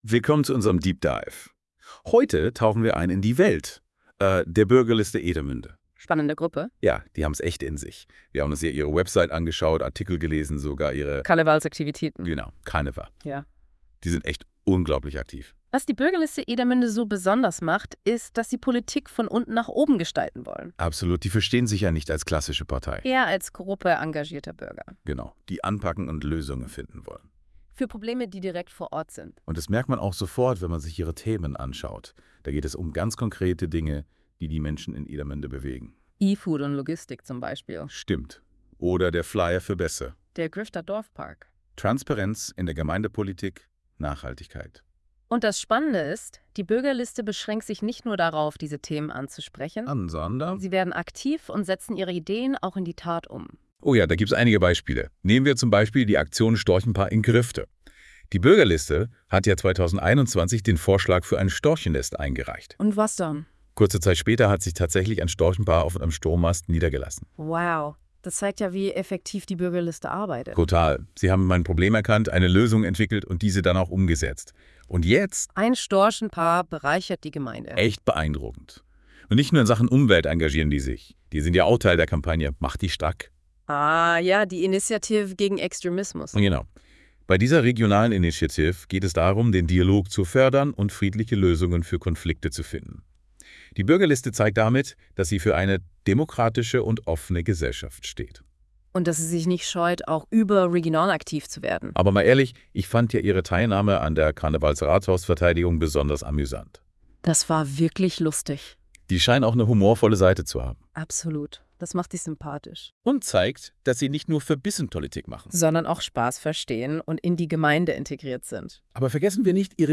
Details Kategorie: Podcast Podcast über die Aktivitäten der Bürgerliste Edermünde Hinweis: Dieser Podcast wurde mit Hilfe einer künstlichen Intelligenz erzeugt.